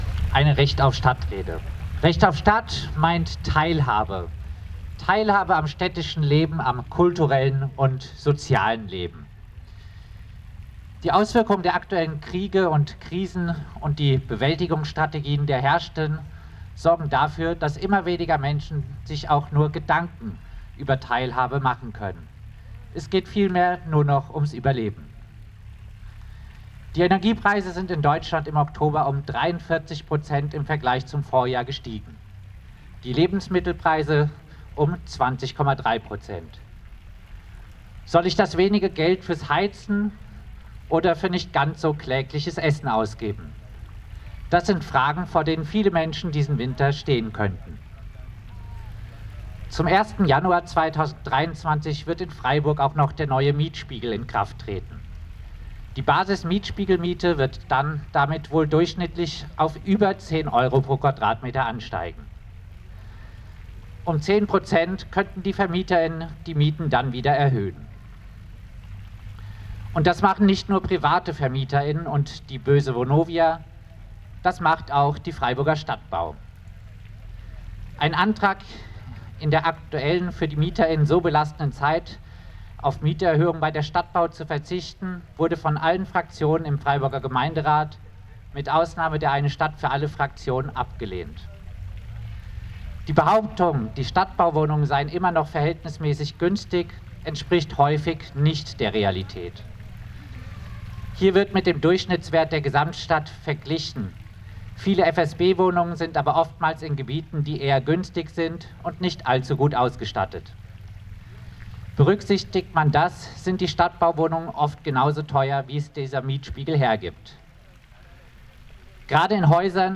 Demo am Samstag den 12.11. vom Stühlinger Kirchplatz in die Innenstadt: "Die Krisen, die uns so zu schaffen machen, sind systemischer Natur!"